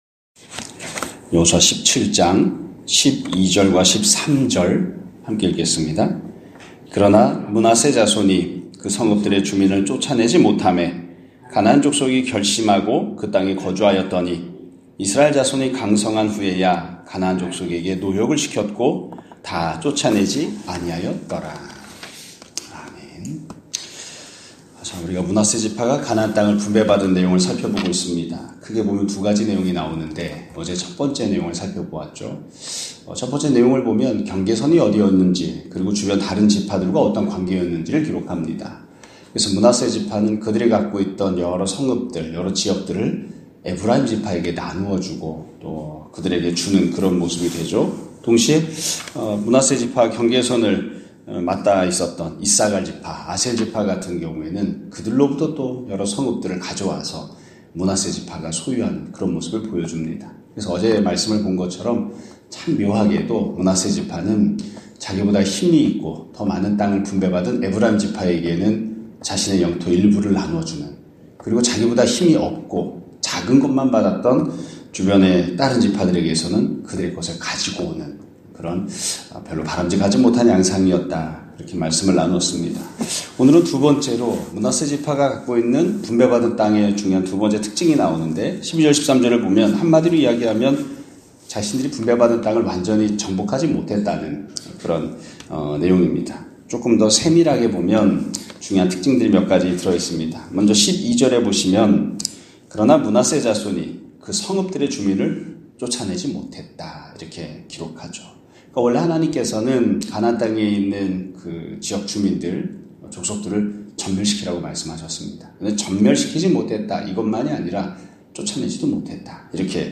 2024년 12월 17일(화요일) <아침예배> 설교입니다.